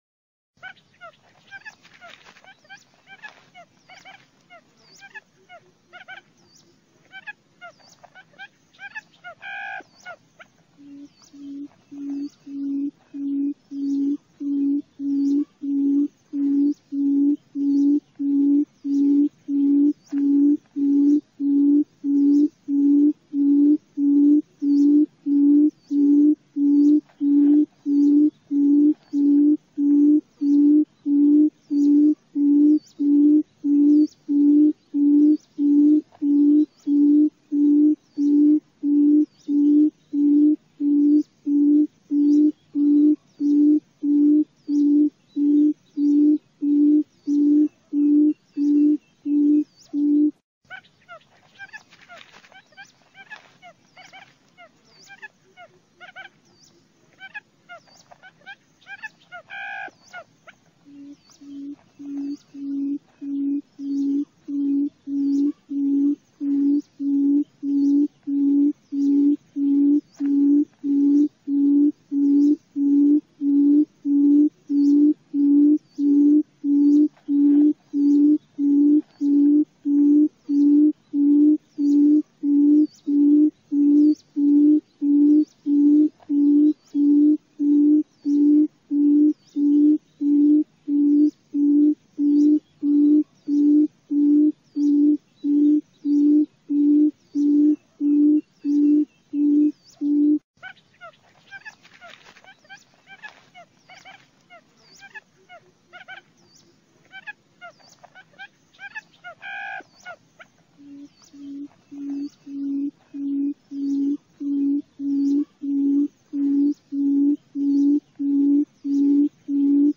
Tiếng Cút mới
Download tiếng Cút mp3, chất lượng âm thanh rõ nét. Âm thanh thiên nhiên từ tiếng Cút mang lại cảm giác gần gũi, thân thuộc, góp phần tạo nên không khí tự nhiên cho tác phẩm của bạn.
File âm thanh tiếng Cút mới chuẩn là phiên bản cập nhật nhất, được thu âm thực tế với độ chi tiết cực cao, giúp tái hiện giọng kêu tự nhiên nhất của loài chim này mà không lẫn tạp âm đô thị.
Âm thanh đã được tối ưu hóa dải tần, loại bỏ hoàn toàn các tiếng "xì" và nhiễu nền, giúp bạn dễ dàng sử dụng ngay mà không cần xử lý thêm qua các phần mềm phức tạp.